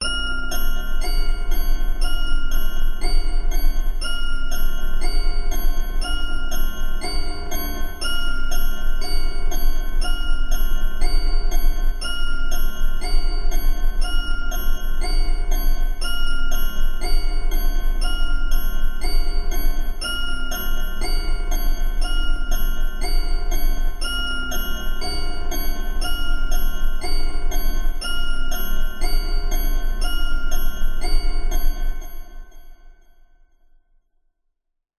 Звук мрачных часов, жутко тикающих во тьме, леденящие акценты